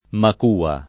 makua [ mak 2 a ] o macua [id.]